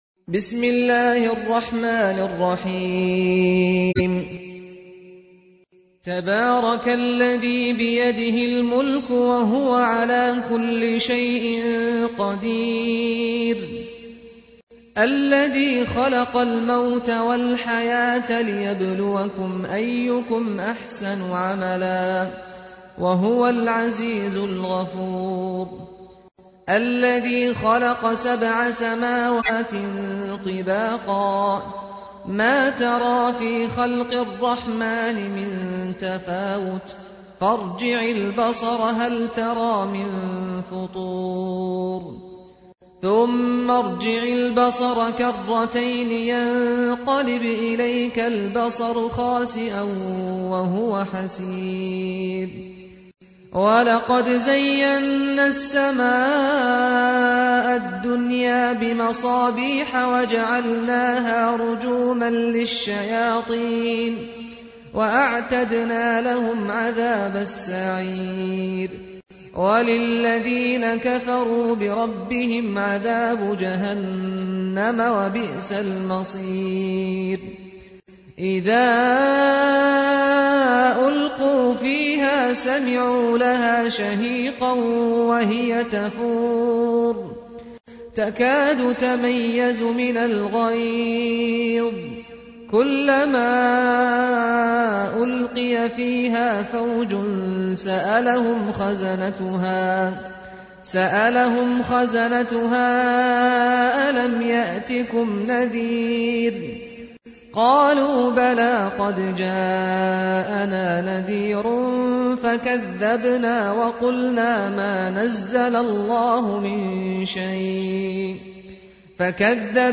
تحميل : 67. سورة الملك / القارئ سعد الغامدي / القرآن الكريم / موقع يا حسين